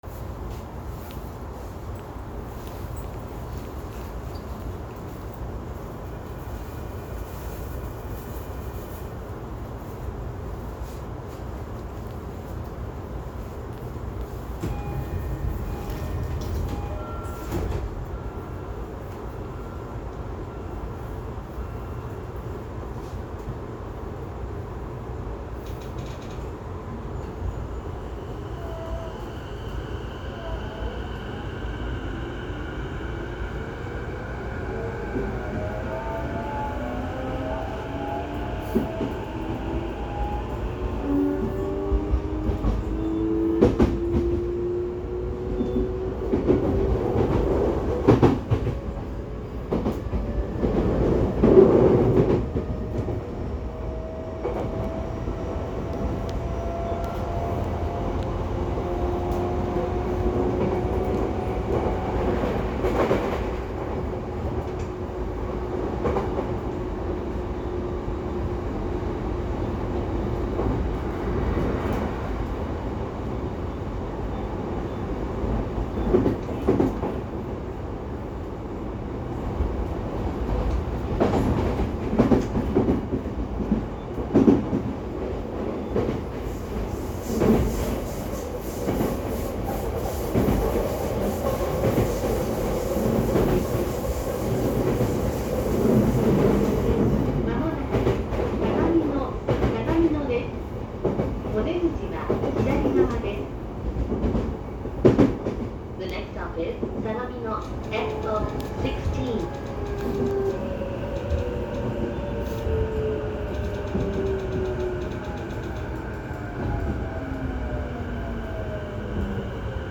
・12000系走行音
【新横浜線】西谷→羽沢横浜国大
これだけ個性的な車両ではありますが、音だけ切り取って聞いてしまうとE233系そのままの三菱IGBTなので途端に無個性になってしまいます。